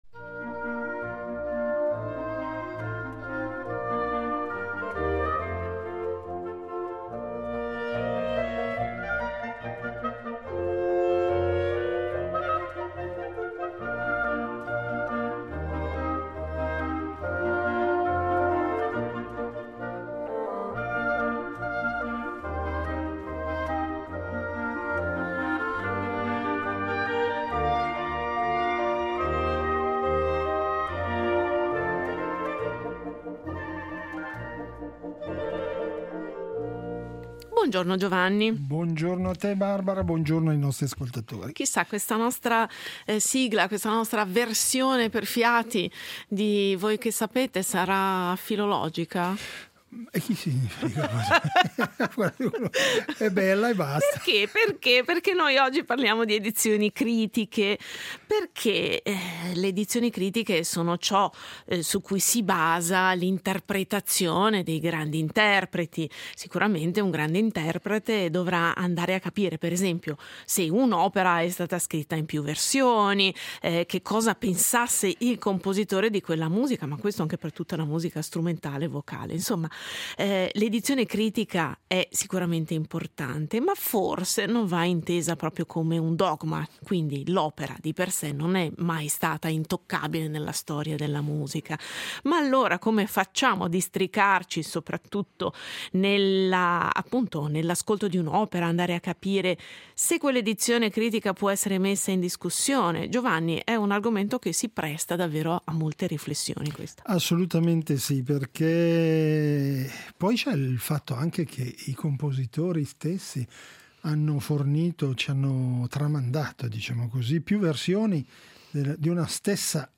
Risponderemo a queste domande e cercheremo di fornire esempi efficaci con gli ospiti odierni